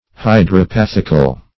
Search Result for " hydropathical" : The Collaborative International Dictionary of English v.0.48: Hydropathic \Hy`dro*path"ic\, Hydropathical \Hy`dro*path"ic*al\, a. Of or pertaining to hydropathy.